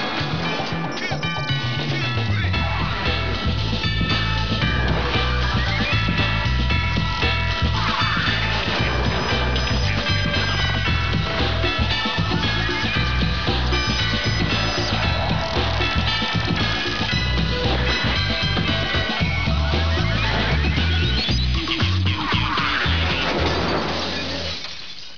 The theme tune from the show in mp3 format.